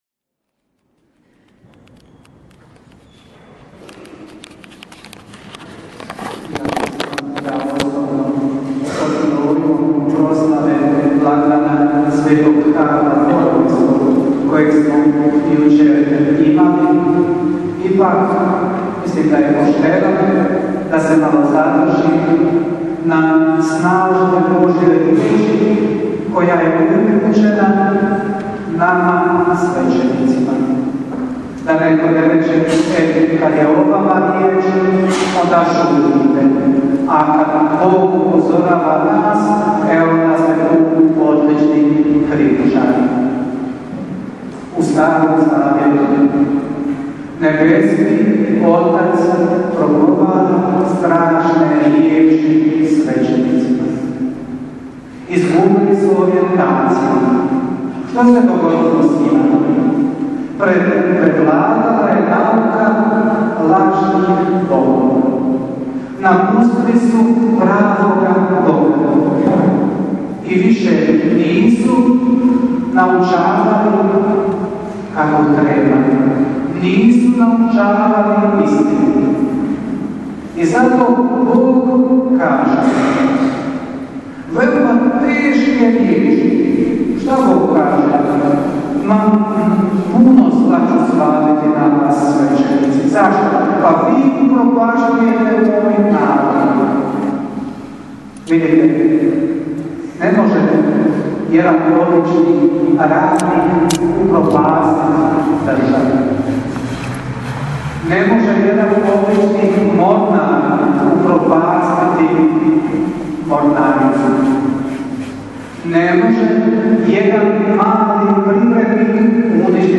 PROPOVJED: